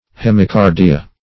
Search Result for " hemicardia" : The Collaborative International Dictionary of English v.0.48: Hemicardia \Hem`i*car"di*a\, n. [NL.